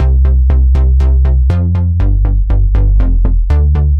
Index of /musicradar/french-house-chillout-samples/120bpm/Instruments
FHC_NippaBass_120-C.wav